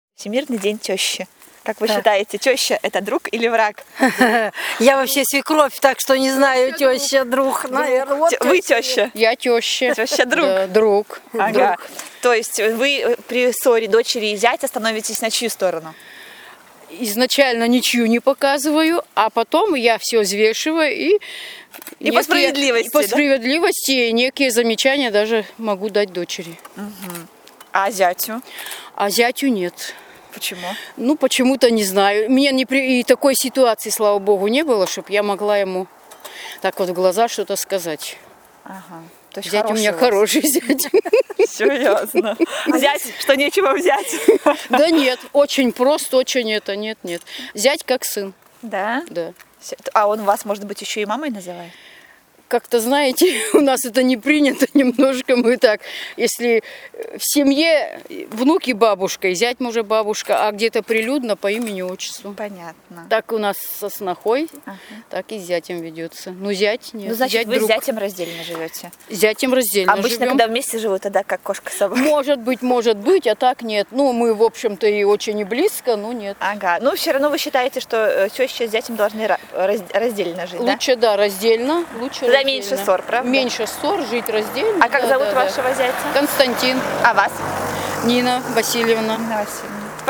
Корреспонденты районной газеты «ГК» провели опрос «Тёща: друг или враг?»: